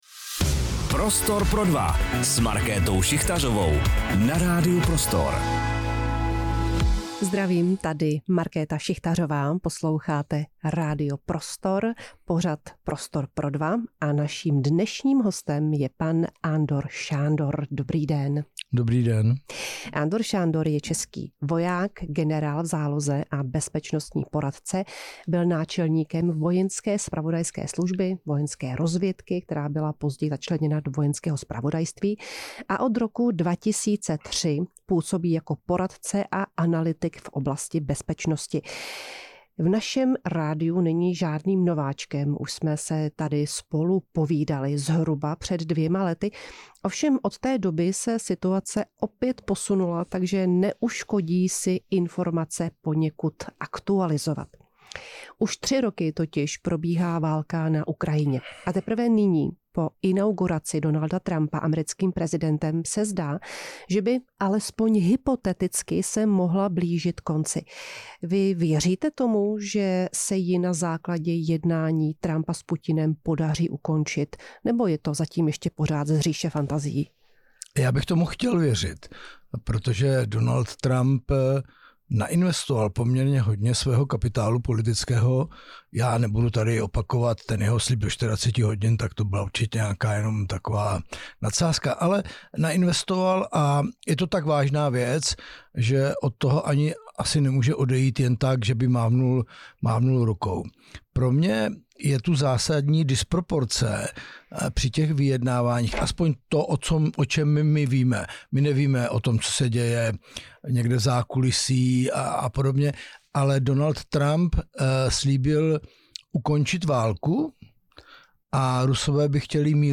Blíží se válka na Ukrajině ke svému konci? Rozhovor s Andorem Šándorem | Radio Prostor